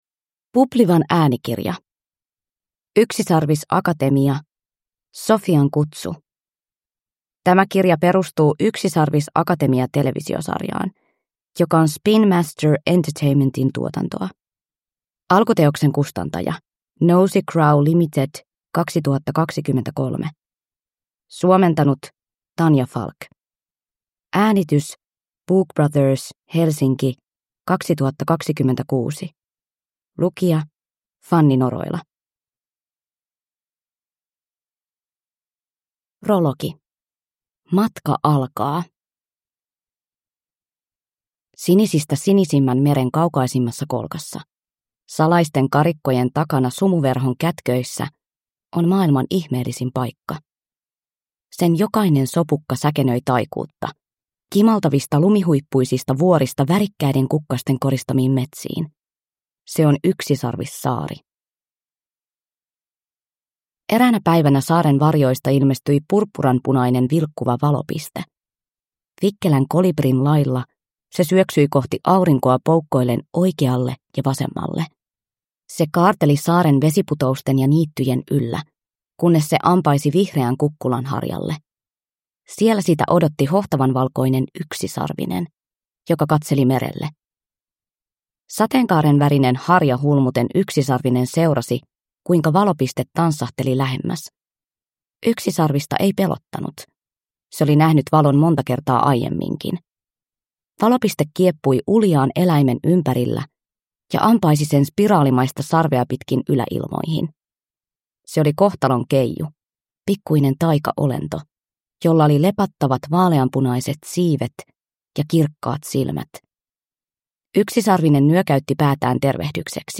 Yksisarvisakatemia - Sophian kutsu – Ljudbok